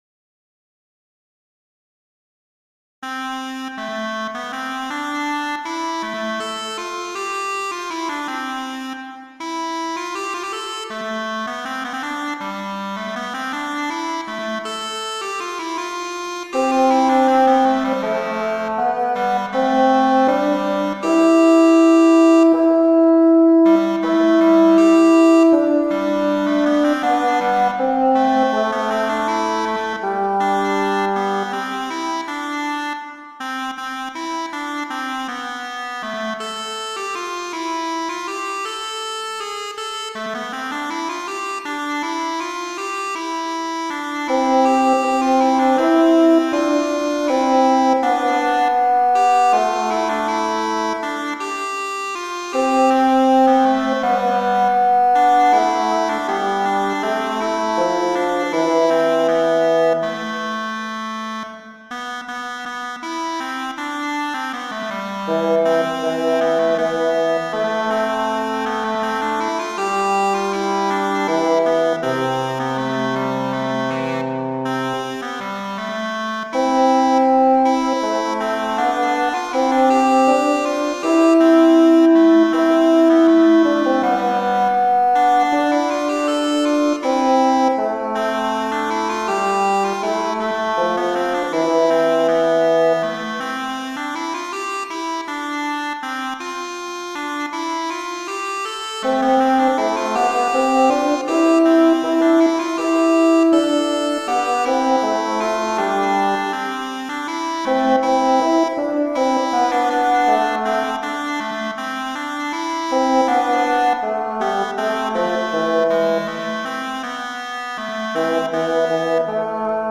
das selbe Stück ohne die erste Stimme als
Playback-Version zum Mitspielen